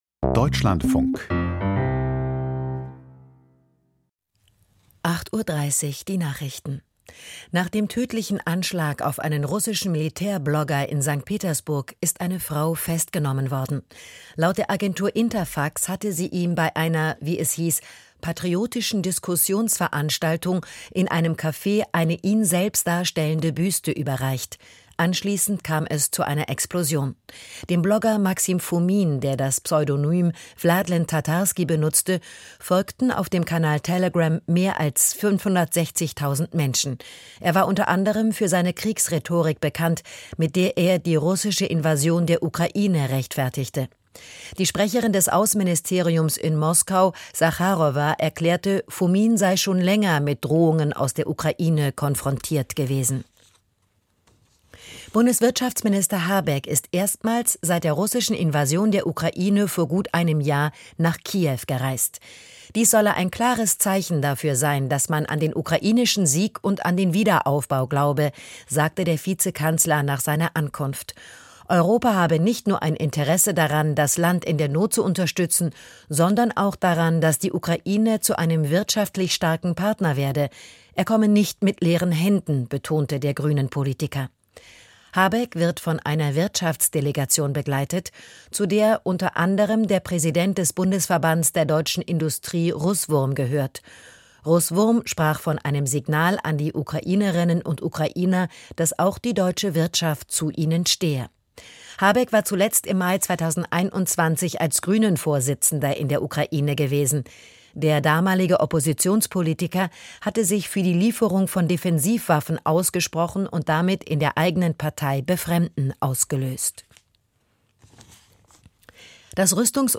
Nachrichten vom 03.04.2023, 08:30 Uhr